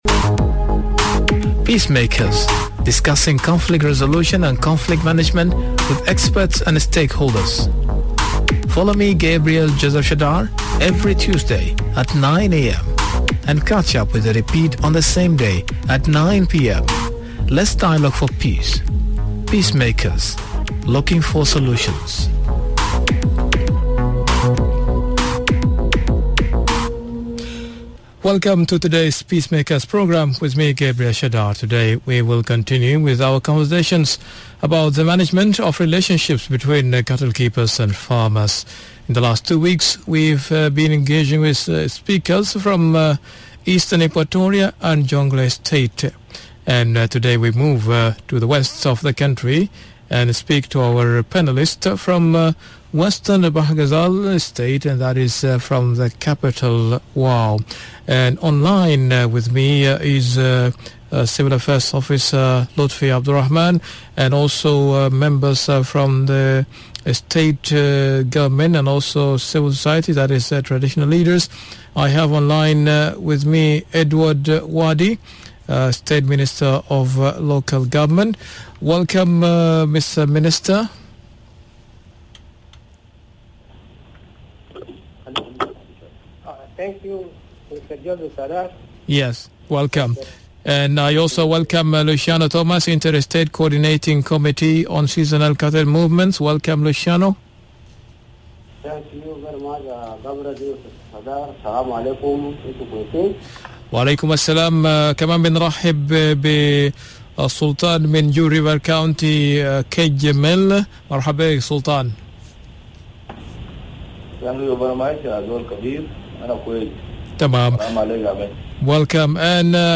In the past two weeks we've been engaging with Eastern Equatoria and Jonglei State. Today's conversation moves to the Western parts of the country with panelists from Wau.